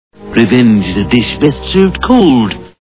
The Simpsons [Burns] Cartoon TV Show Sound Bites